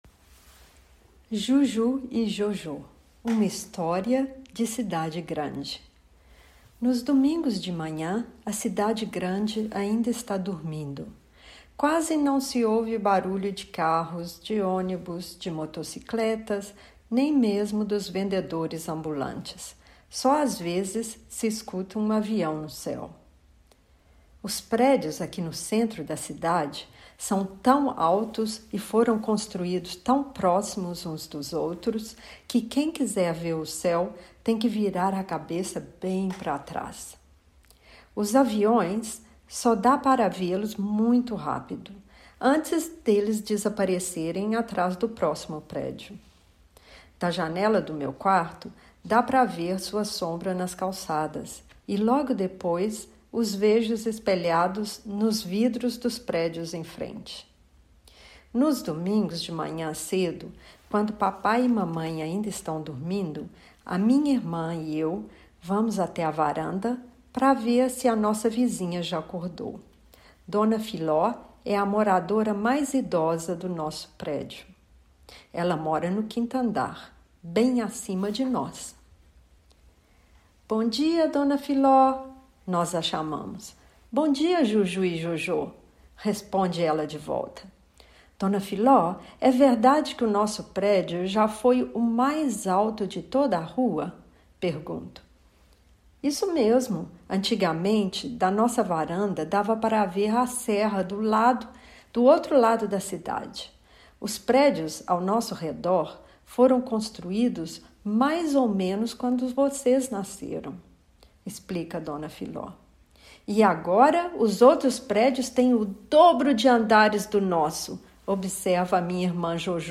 Portugiesischer Hörtext Juju y Jojô